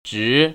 [zhí] 즈